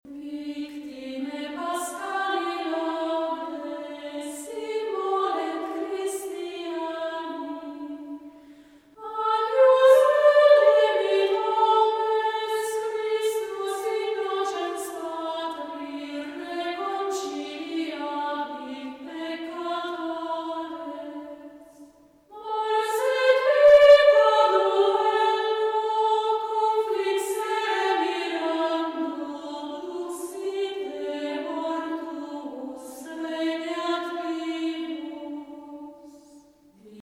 "Victimae Paschali Laudes" Canto gregoriano
Il canto gregoriano, per sua natura, non è propriamente un canto, ma una proclamazione solenne del testo sacro: costituisce una sorta di amplificazione sonora del testo stesso, che non viene semplicemente "detto", ma sottolineato attraverso tecniche che, tra le arti, appartengono più all'oratoria che alla musica.
Ensamble San Felice
gregoriano.mp3